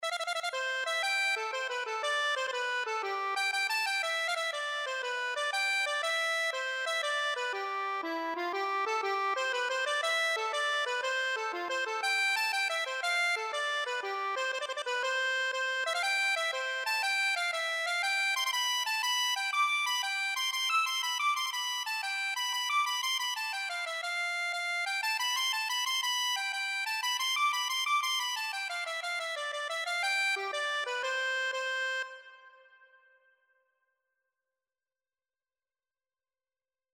Traditional Turlough O Carolan Lady Athenry Accordion version
C major (Sounding Pitch) (View more C major Music for Accordion )
6/8 (View more 6/8 Music)
.=120 Graciously
Accordion  (View more Easy Accordion Music)
Traditional (View more Traditional Accordion Music)